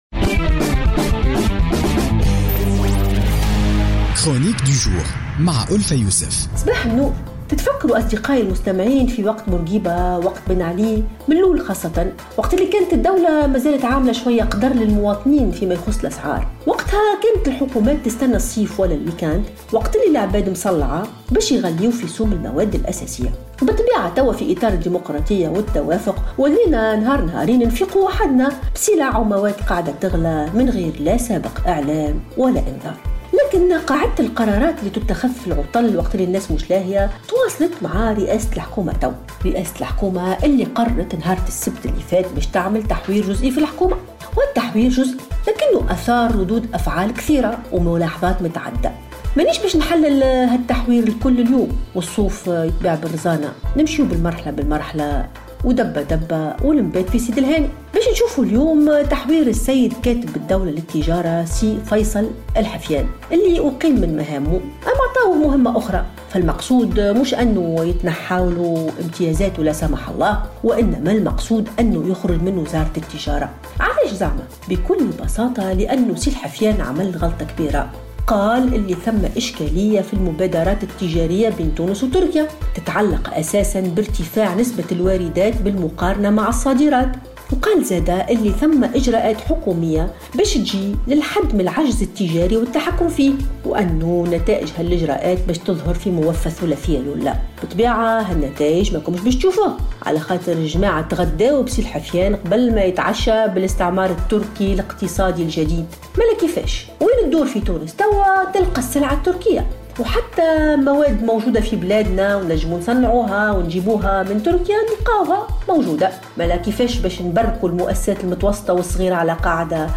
تطرقت الكاتبة ألفة يوسف في افتتاحية اليوم الثلاثاء 28 فيفري 2017 إلى التحوير الوزاري الذي أجراه يوسف الشاهد السبت الماضي مستغلا انشغال التونسيين ببدء العطلة الأسبوعية والمدرسية مشيرة إلى أن عادة قرارات العطل هذه كان معمولا بها في عهد بورقيبة عندما كان يراد الترفيع في أسعار المواد الأساسية في غفلة من الجميع.